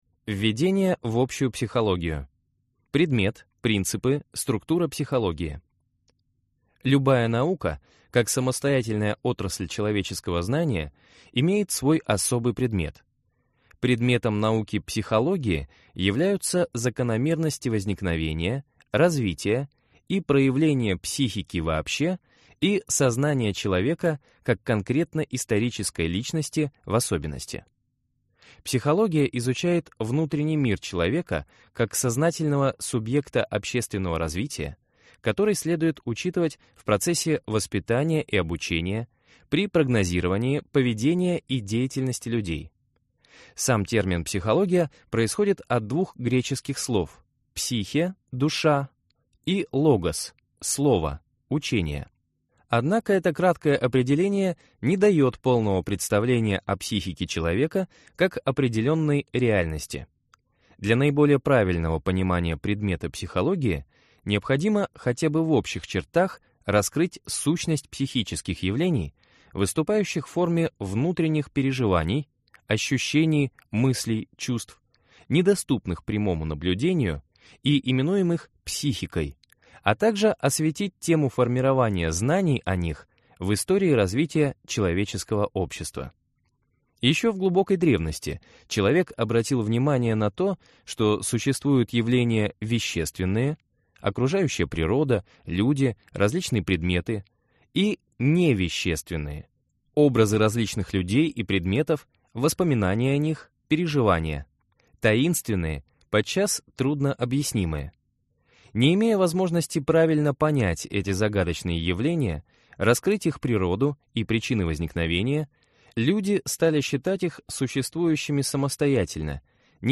Аудиокнига Общая психология. Курс лекций | Библиотека аудиокниг